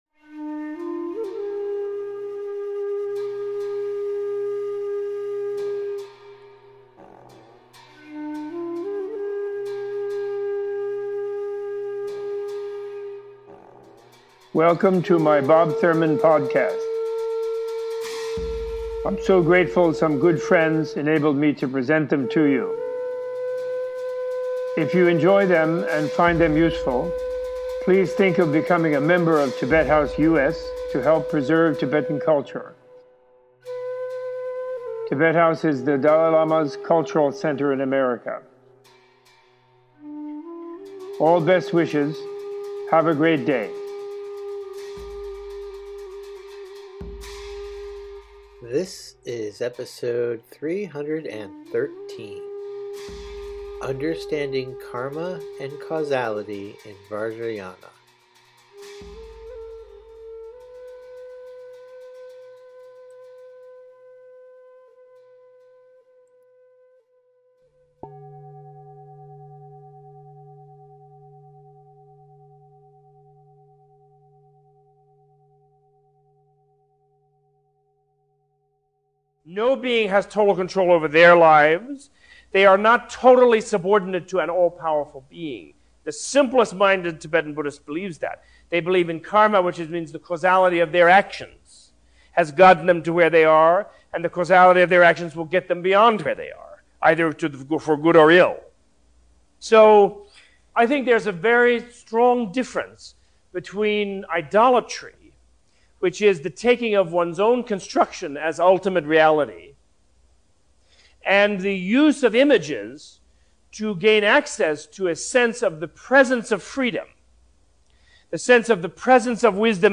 Opening with a discussion of idolatry and Buddhist ethics, Robert Thurman gives a teaching on karma and the causality of action as understood in Tibetan and Vajrayana Buddhism. This episode includes a short history of Buddhist ethics and schools across Asia and the establishment and development of Vajrayana Buddhism in Tibet.